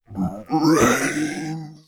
ZOMBIE_Moan_09_mono.wav